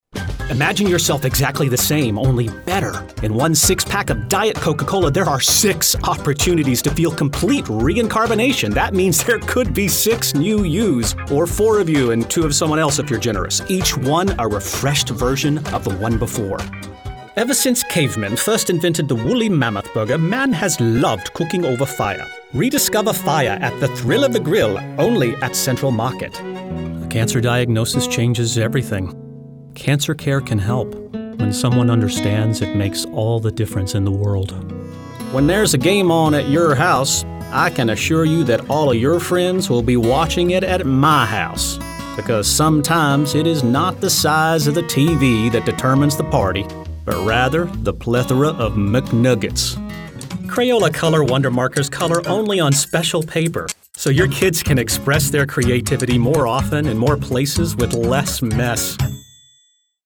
Voice Over Demo Online!
Together, we cut eight spots in a couple of hours and produced a demo with which I’m quite pleased.